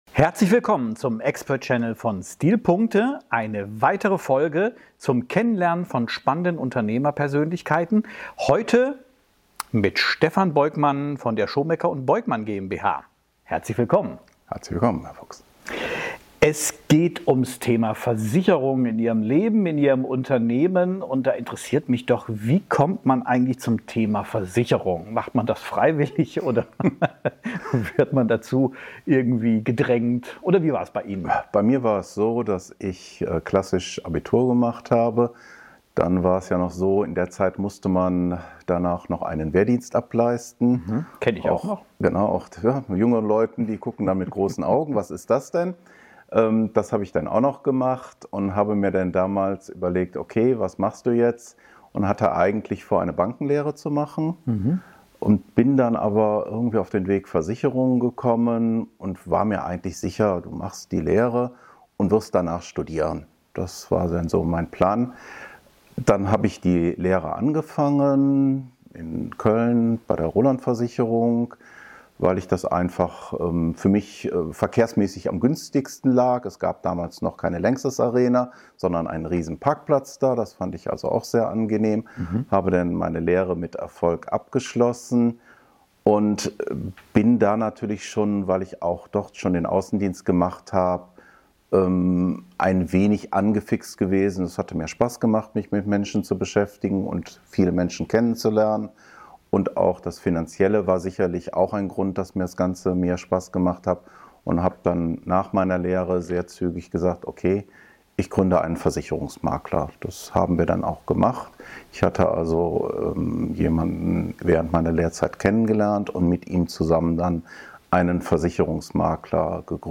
Dieses Interview bietet wertvolle Einblicke in die Bedeutung von Entscheidungen für die berufliche Laufbahn und die Rolle persönlicher Interessen im Unternehmertum.